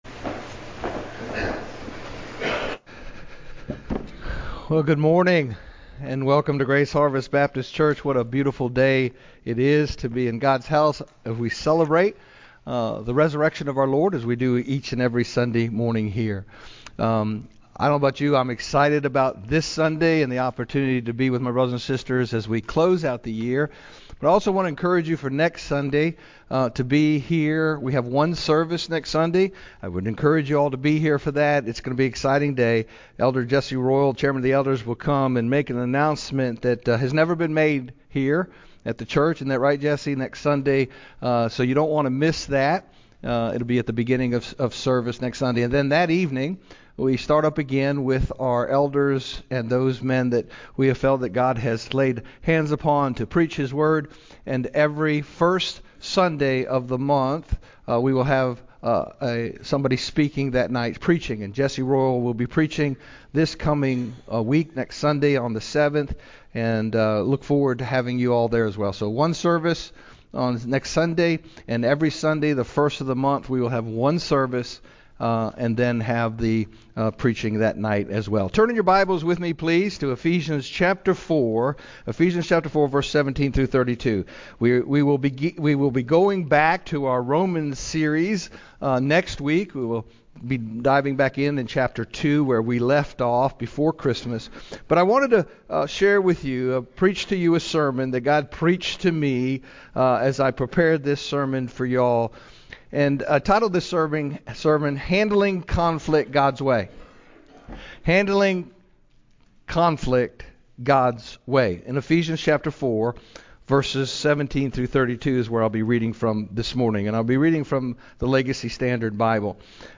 GHBC-Service-Handling-Conflict-Gods-Way-Eph-417-32-CD.mp3